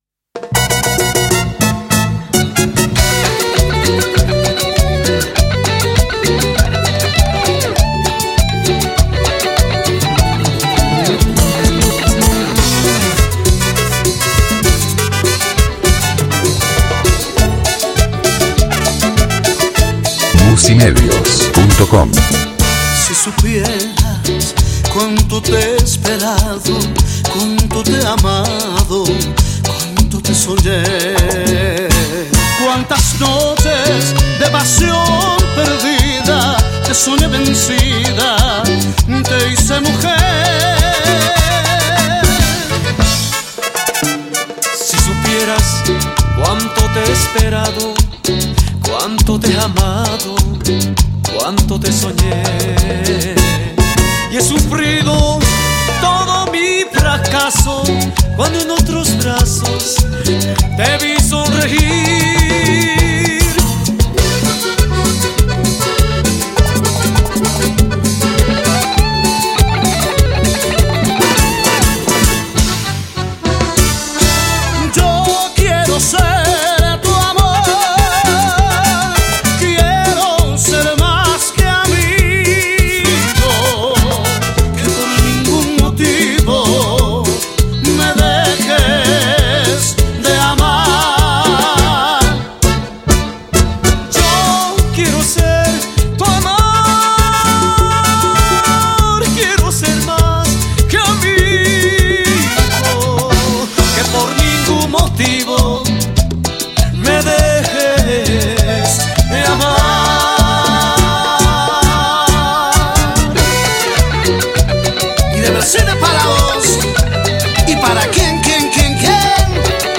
Genre Cumbia Latina